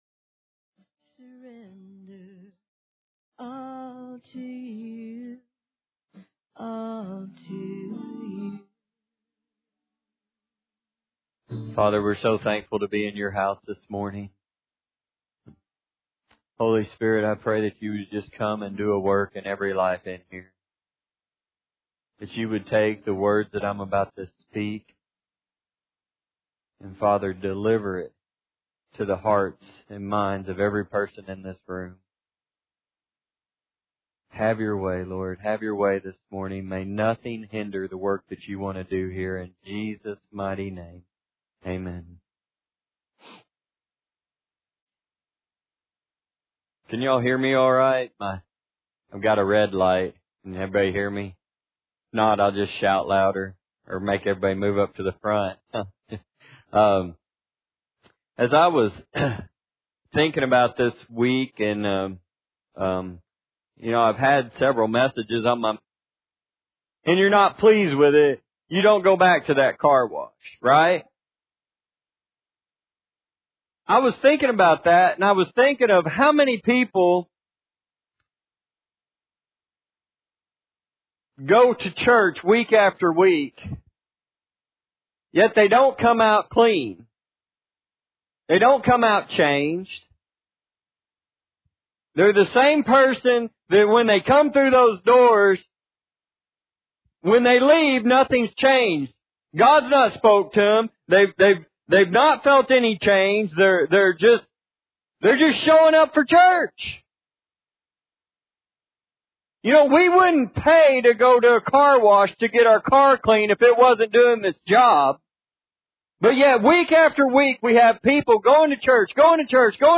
Matthew 3:11 Service Type: Sunday Morning Bible Text